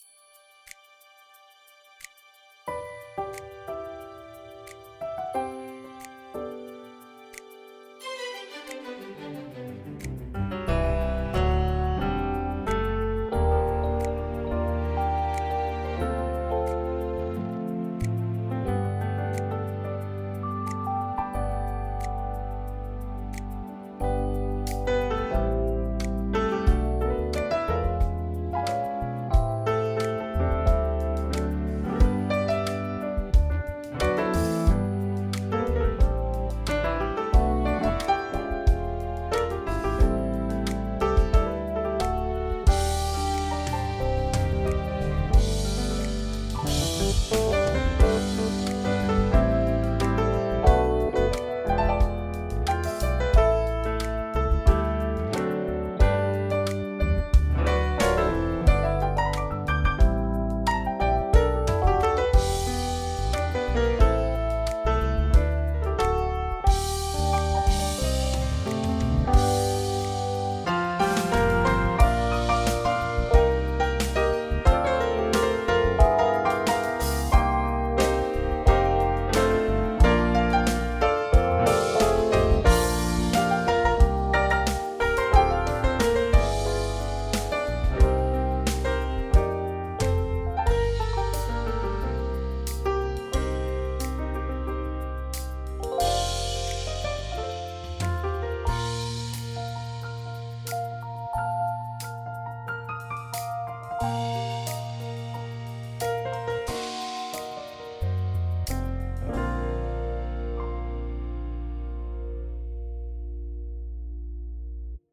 # AudioMo : Here's a clip from # NottingHillCarnival 2005, when my house was within the boundary of the event. Every year I would hang microphones out of my bedroom window for the two days, set up a live-stream and let it rip.
In this recording you can hear a passing band of drummers going down the road next to mine.